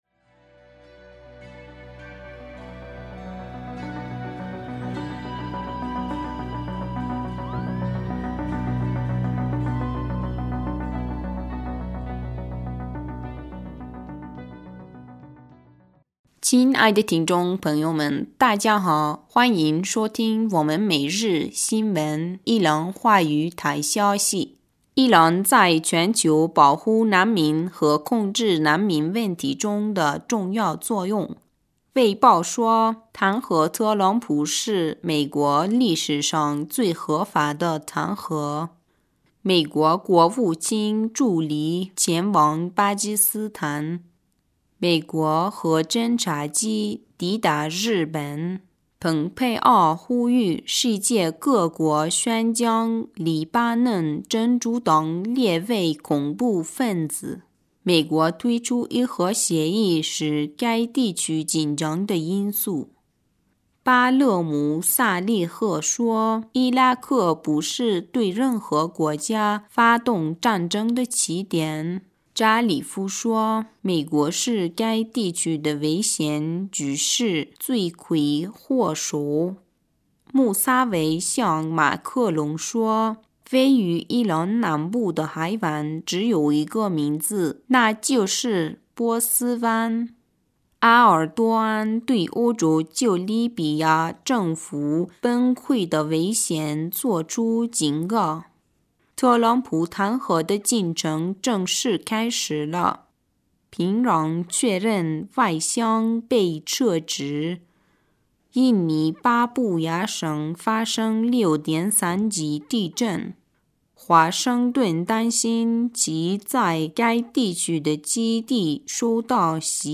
2020年1月19日 新闻